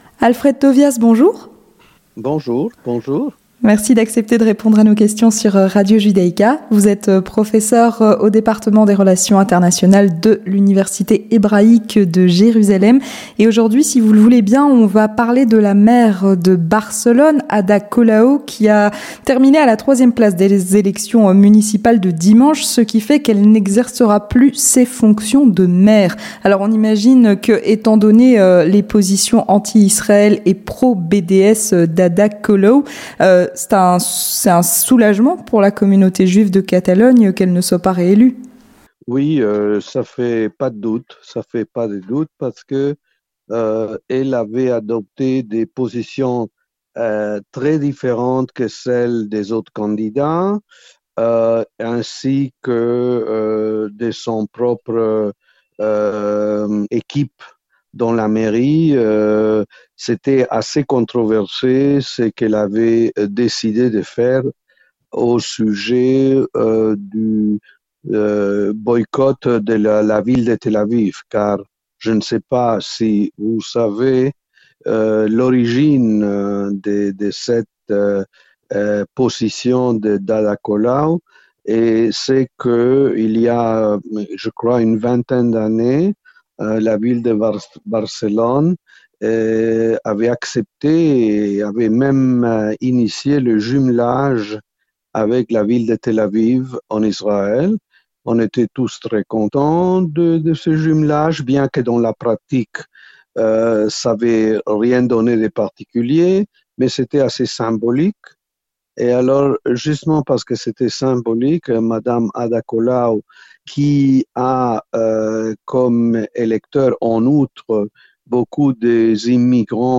Entretien du 18h - Ada Calau perd la mairie de Barcelone